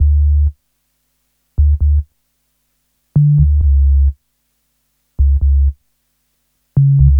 HP133BASS1-R.wav